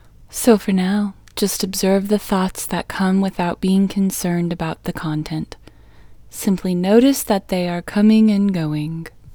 LOCATE OUT English Female 8